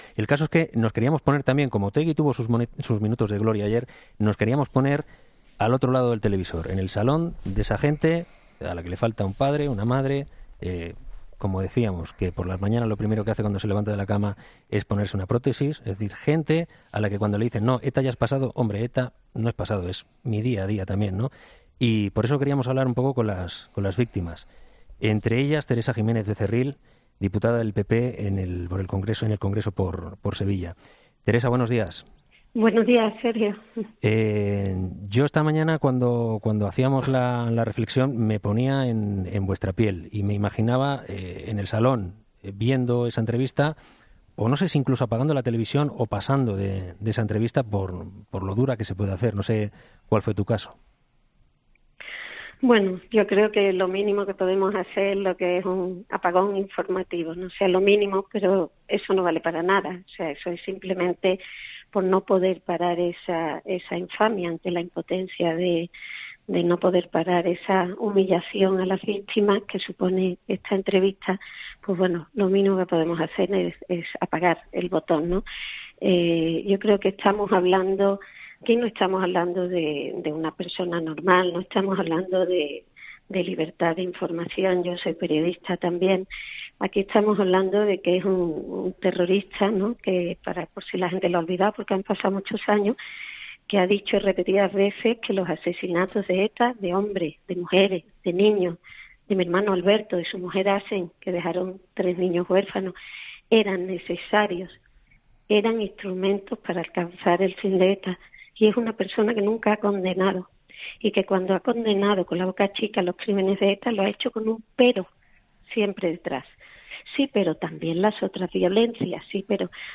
La entrevista de ayer en el Canal 24h de RTVE a Arnaldo Otegi, lider de EH Bildu, ha provocado las críticas de numerosos partidos políticos. Para comentar esta entrevista ha participado en 'Herrera en COPE' Teresa Jiménez Becerril, hermana de Alberto Jiménez-Becerril, asesinado por ETA, y diputada del Partido Popular en el Congreso de los Diputados.